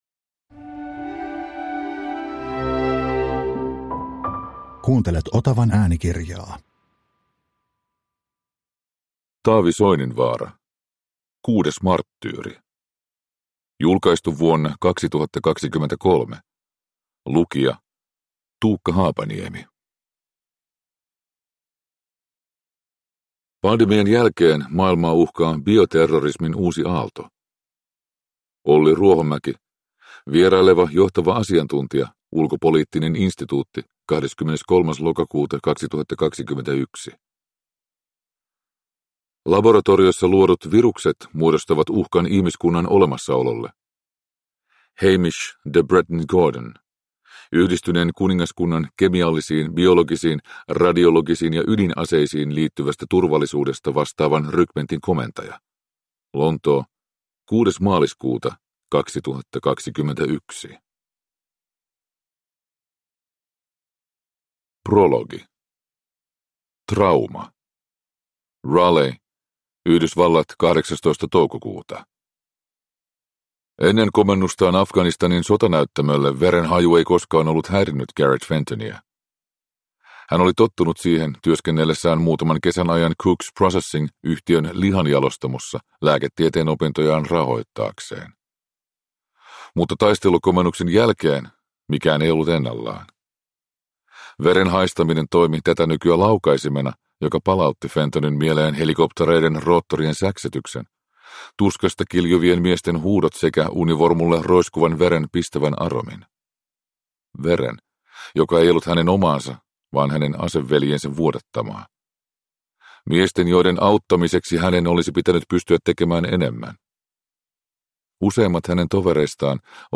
Kuudes marttyyri – Ljudbok – Laddas ner